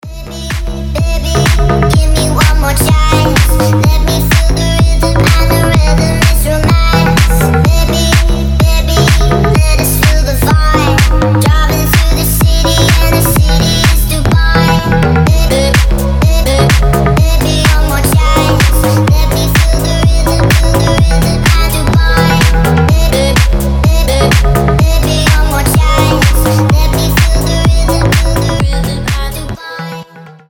deep house
забавные
EDM
басы
slap house